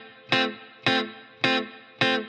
DD_TeleChop_105-Fmaj.wav